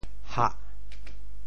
铪（鉿） 部首拼音 部首 钅 总笔划 11 部外笔划 6 普通话 hā kē 潮州发音 潮州 hah4 白 中文解释 铪 <名> 化学元素名,化学上与锆非常相似的一种灰色高熔点四价金属元素,它存在于大多数锆矿中,由于它容易发射电子而很有用处(如用作白炽灯的灯丝) [hafnium]――元素符号Hf 铪 ke 〔～匝〕周匝，环绕，如“紫帷～～，翠屏环合”。